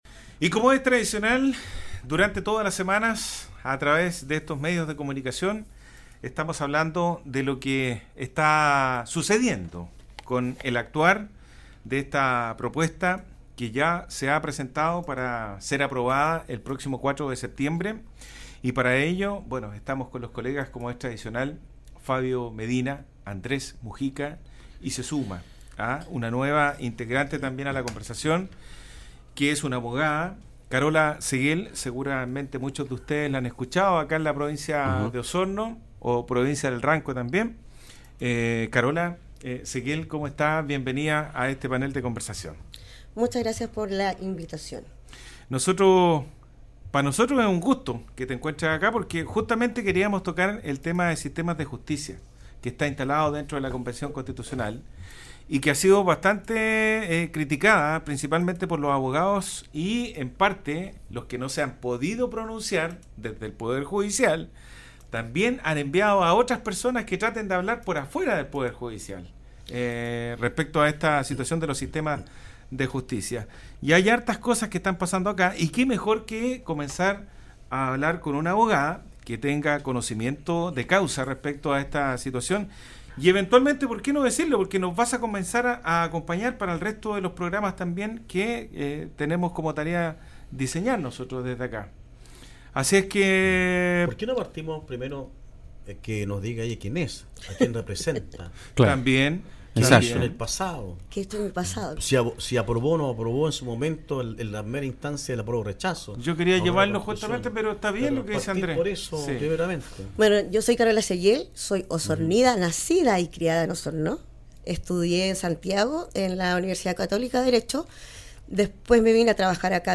Conversación que nació durante el tiempo que funcionó la Convención Constituyente encargada de confeccionar la Nueva Constitución para Chile, terminado ese proceso, el análisis y las entrevistas continúan, ahora en vistas al Plebiscito de salida.